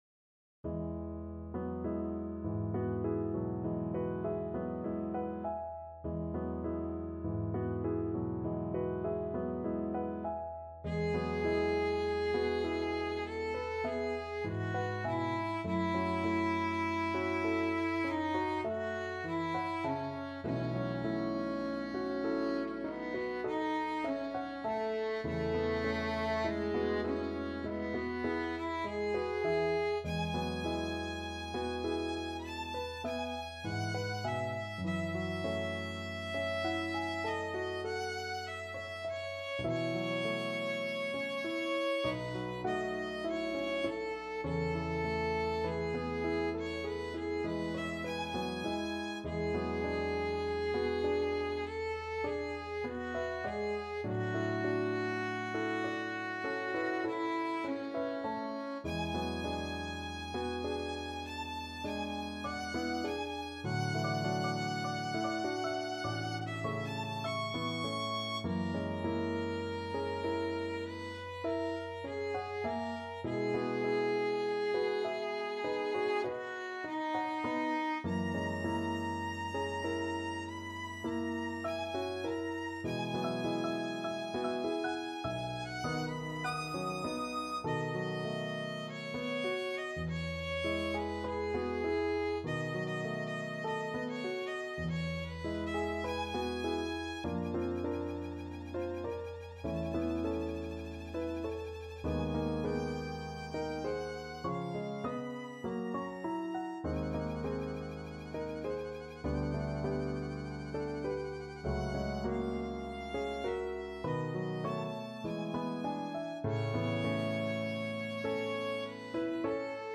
Classical Rimsky-Korsakov, Nikolai Nocturne from Pan Voyevoda Violin version
4/4 (View more 4/4 Music)
E major (Sounding Pitch) (View more E major Music for Violin )
Lento =50
Classical (View more Classical Violin Music)